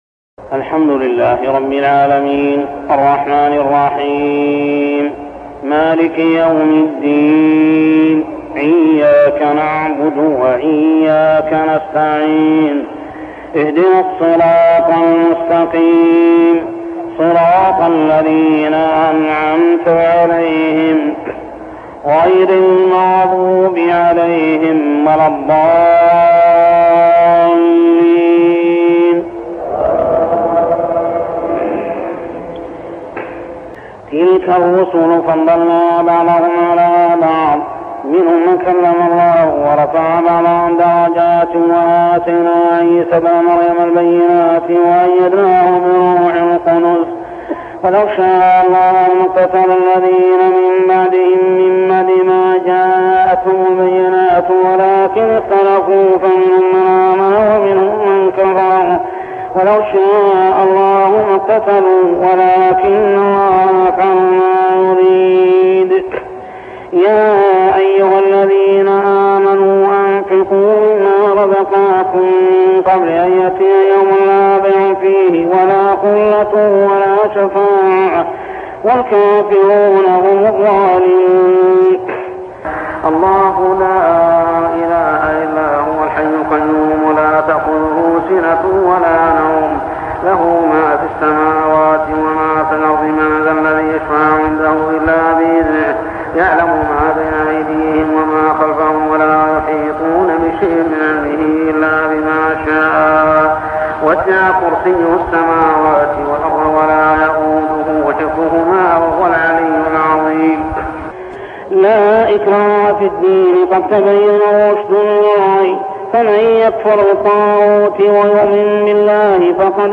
صلاة التراويح عام 1403هـ سورتي البقرة 253-286 و آل عمران 1-14 | Tarawih prayer Surah Al-Baqarah and Al-Imran > تراويح الحرم المكي عام 1403 🕋 > التراويح - تلاوات الحرمين